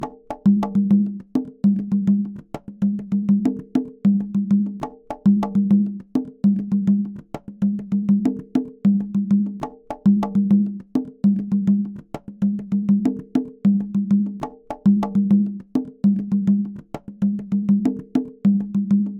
Latin Percussion Example 3
Congas
Conga-Sample.mp3